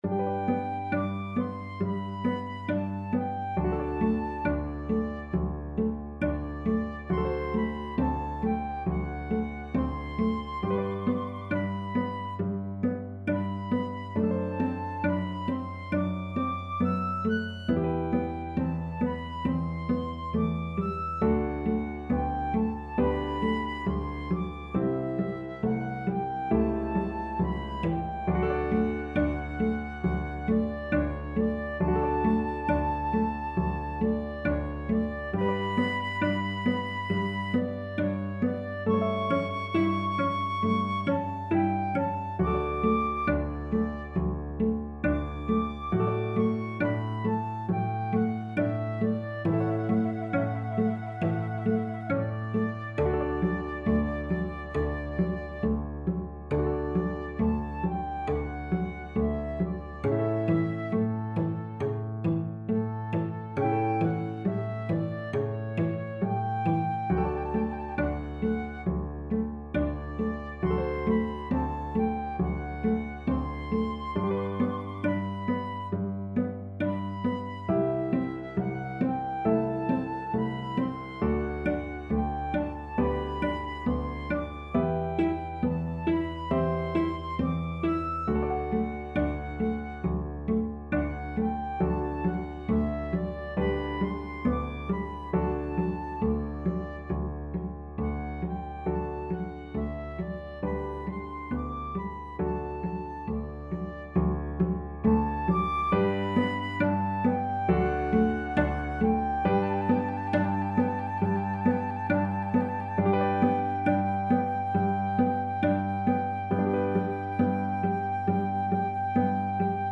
Free Sheet music for Flute-Cello Duet
G major (Sounding Pitch) (View more G major Music for Flute-Cello Duet )
4/4 (View more 4/4 Music)
= 34 Grave
Classical (View more Classical Flute-Cello Duet Music)